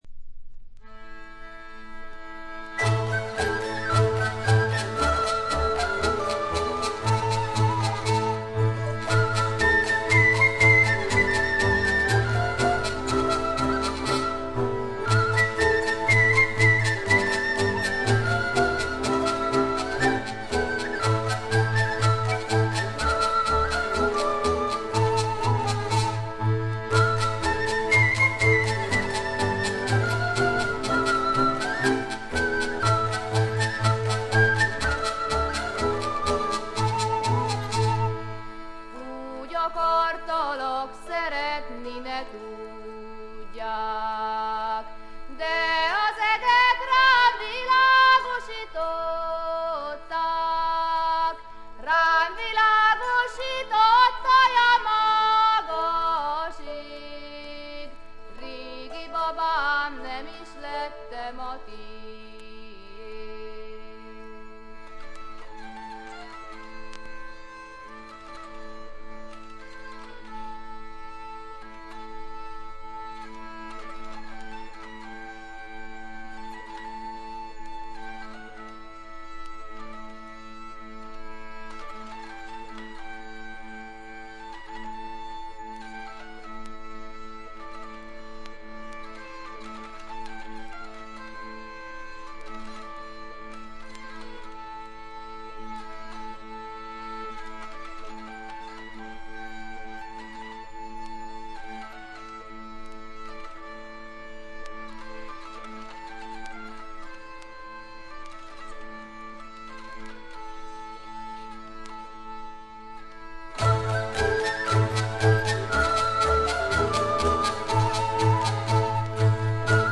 見た目よりちょっと劣り、細かなチリプチや散発的なプツ音は聴かれます。
ハンガリーの男女混成7−8人のトラッド・グループ。
エキゾチックな演奏に力強い女性ヴォーカルが響き合って得も言われぬ世界を描き出しています。
試聴曲は現品からの取り込み音源です。
Voice, Soloist